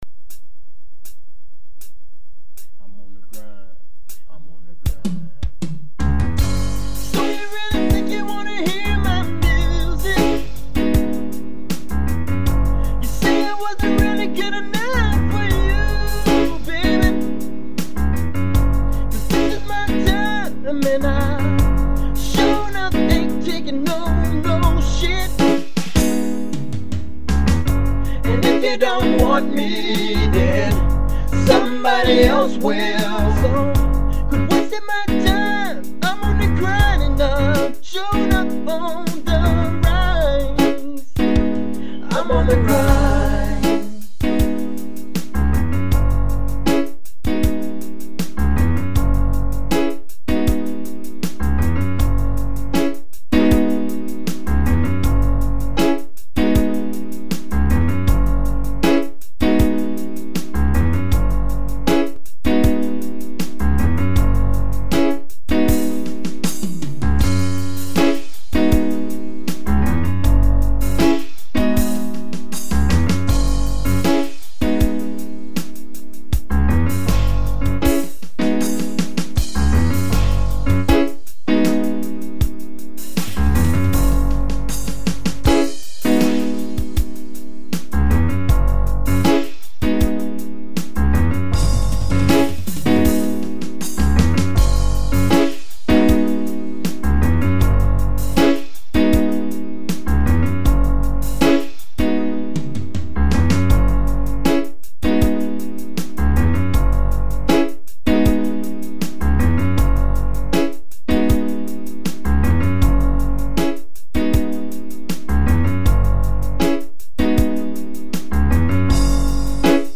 dance/electronic
RnB